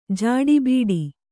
♪ jāḍi bīḍi